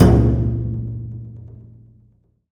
wadaiko1.WAV